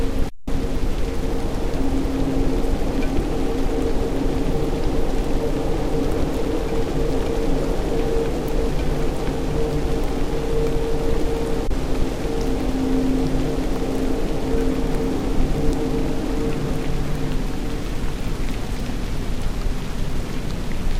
2019 WILTON ENVIRONMENTAL NOISE
Audio intermingled with rain noise?